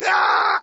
scream14.ogg